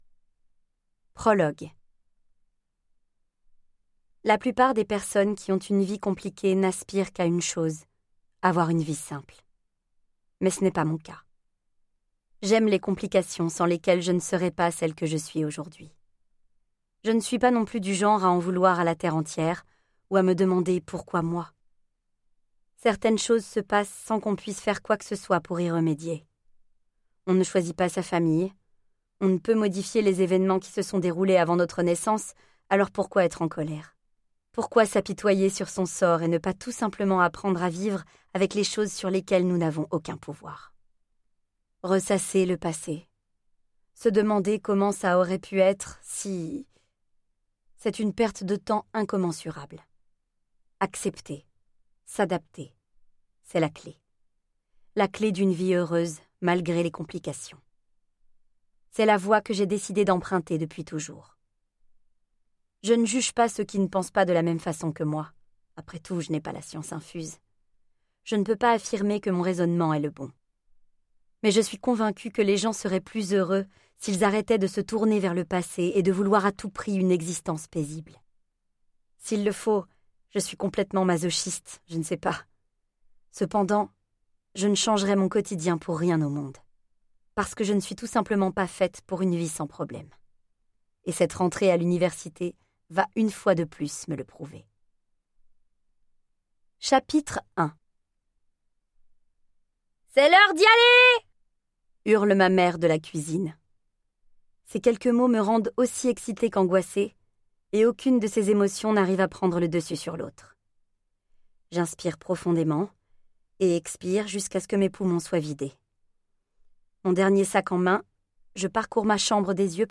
je découvre un extrait - The Devil's Sons, Tome 1 de Chloé Wallerand